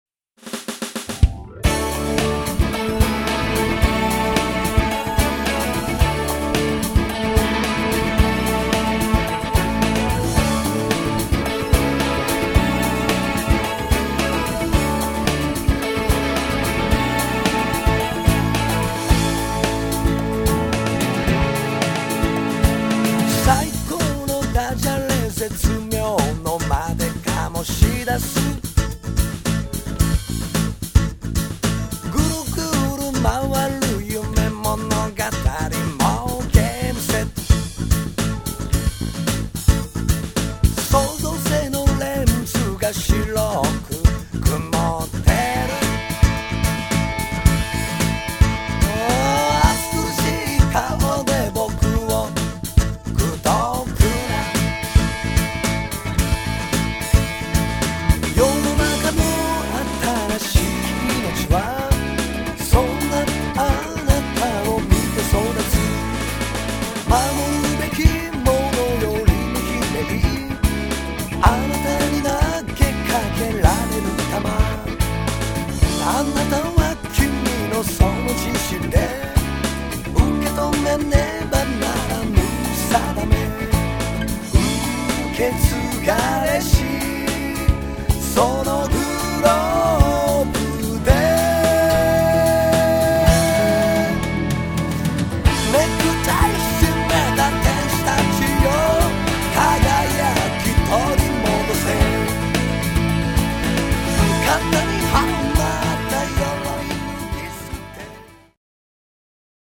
Vo & Aco.G, Harp
E.Bass
E&Aco.Guitar
Percussion
E.Guitar
しゃがれた彼の歌声は健在。